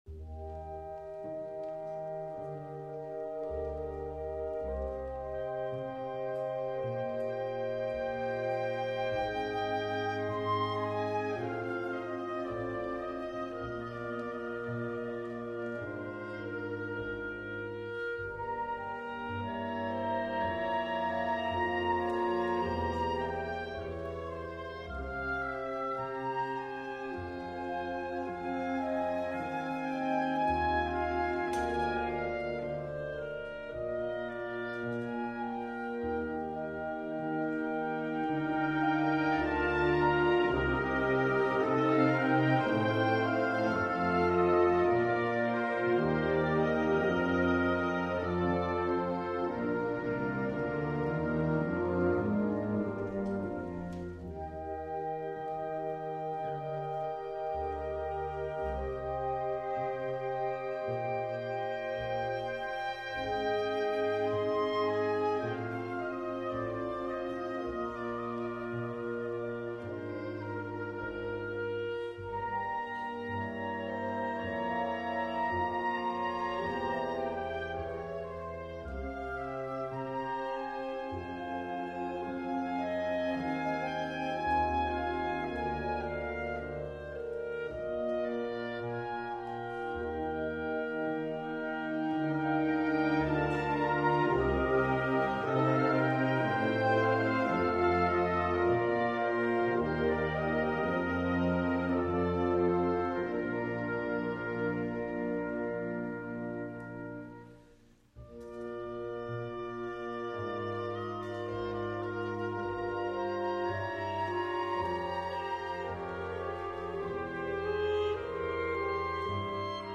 E-flat Major（原曲：D Major）
ひとつの声部を色々な楽器が引き継ぐ、いわゆる音色旋律の技法が多用されています。
少人数バンドのための編曲ですが、大編成バンドで演奏しても効果的です。
（大阪音大短期大学部音楽専攻吹奏楽コース）